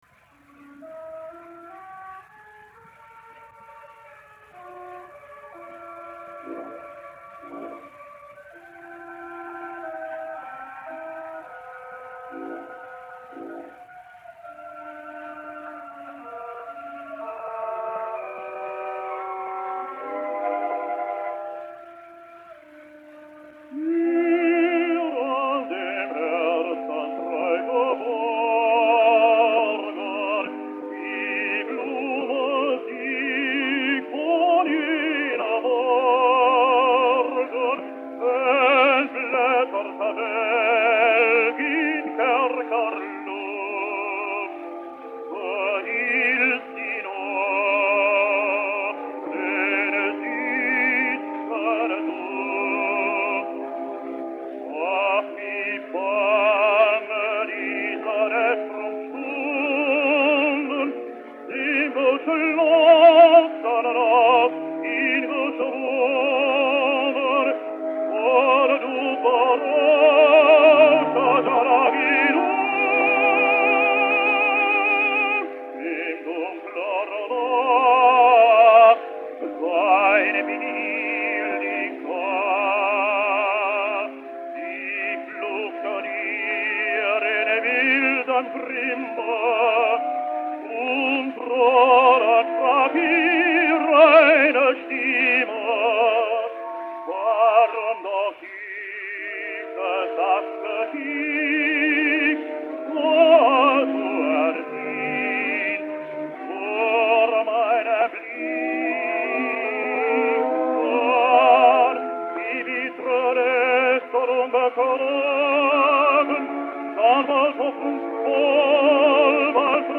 Dutch Tenor.